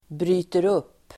Uttal: [bry:ter'up:]